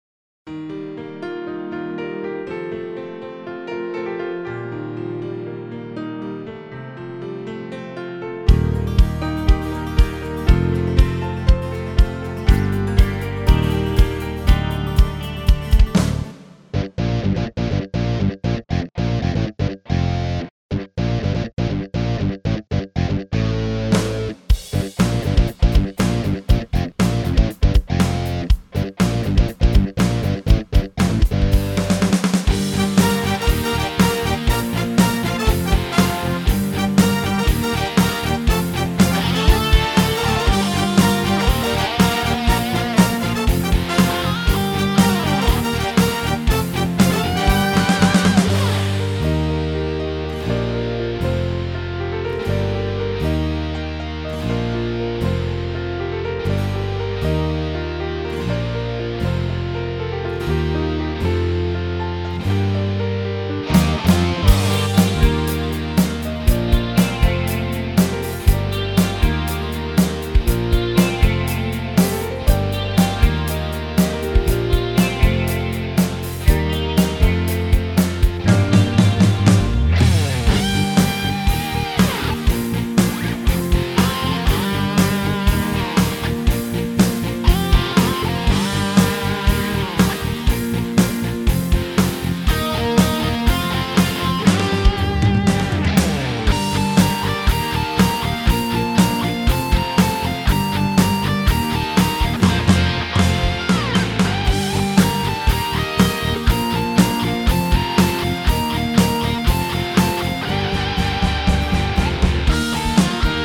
מה דעתכם על מקצב הרוק הזה (והגיטרות) korg pa1000
מצרף איזה קטע מתוך פלייבק שעשיתי בס"ד באורגן korg pa1000 מה דעתכם על זה : SHABICHI PB nn x MM NN v4 smpl.mp3